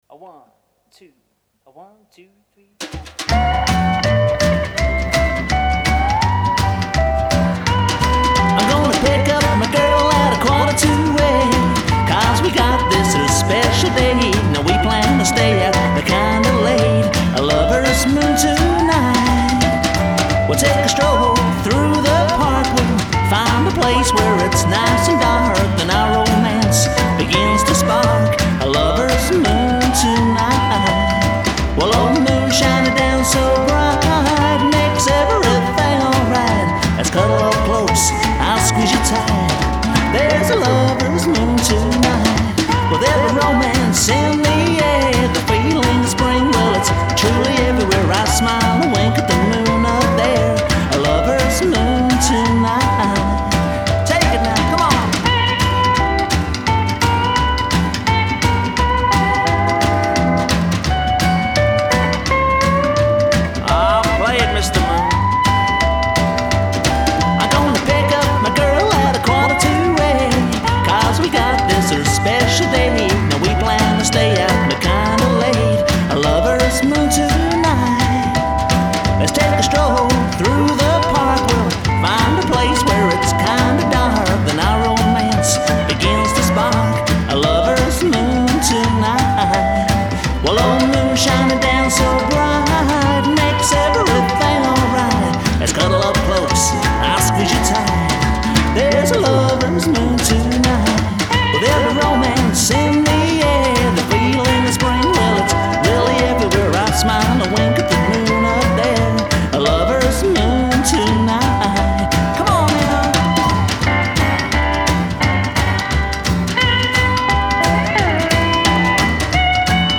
Good solid Rockabilly album.